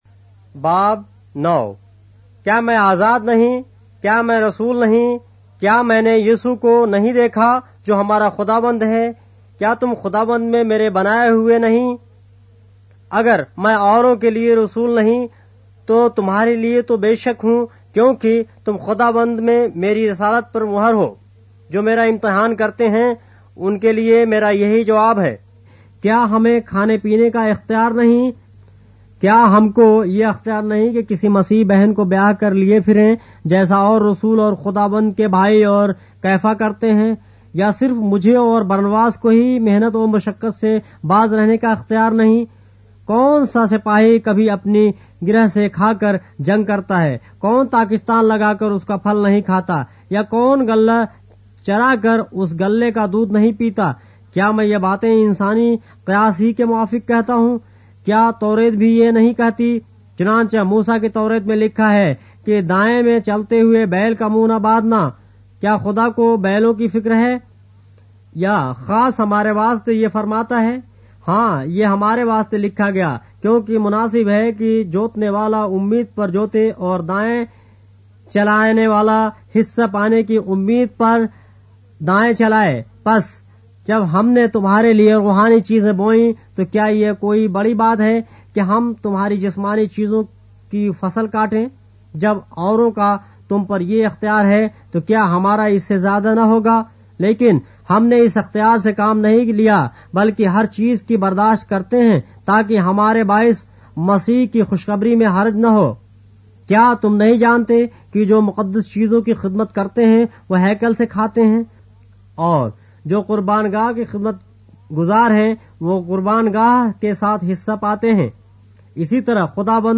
اردو بائبل کے باب - آڈیو روایت کے ساتھ - 1 Corinthians, chapter 9 of the Holy Bible in Urdu